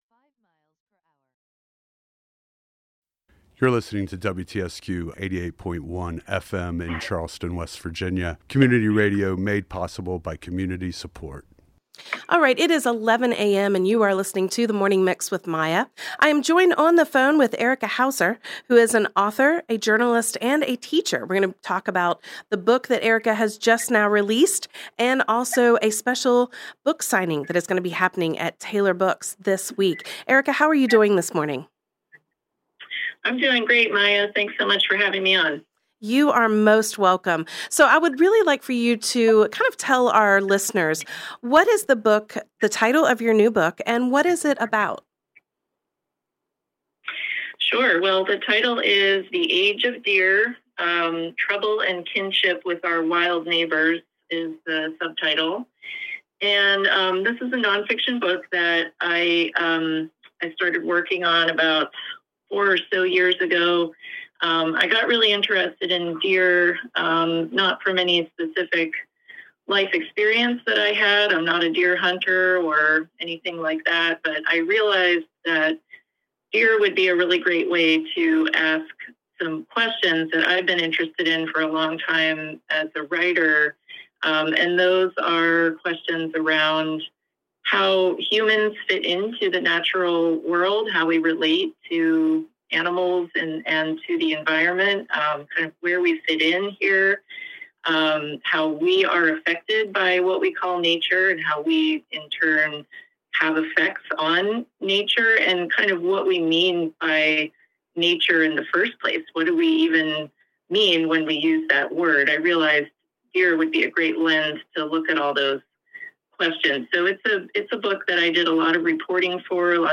Interview with Author